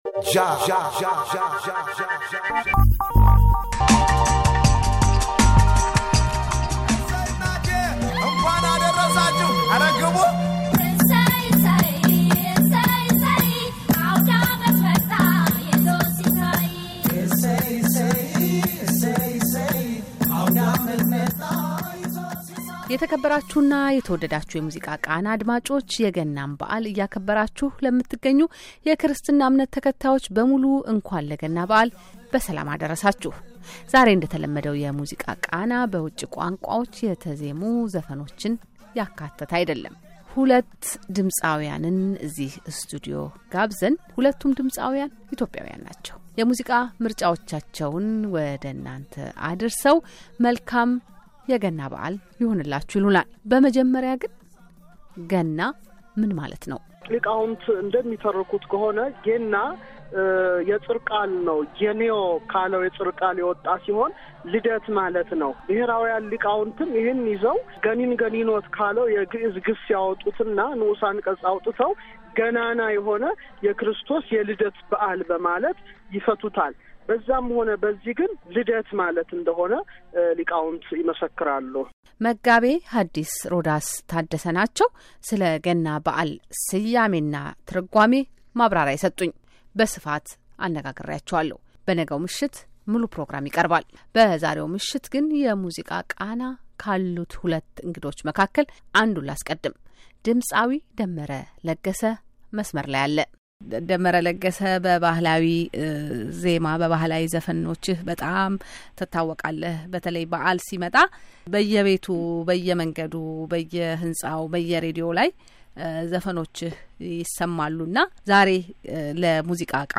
የሙዚቃ ቃና ዘወርት ቅዳሜ ከምሽቱ 3፡30 ከአሜሪካ ድምጽ ሬዲዮ ጣቢያ የአማርኛ ቋንቋ ዝግጅት ክፍል ተቀናበሮ ምርጥ ምርጥ የሆኑ የካንተሪስ፣የሶል፣ የብሉዝ፣ የሬጌ፣ የሂፖፕ እና ሌሎችንም የዜማ ስልቶችን የያዙ ሙዚቃዎች ተከሽኖ ወደ እናንተ ወደ አድማጮች ጆሮ የሚደርስበት ፕሮግራም።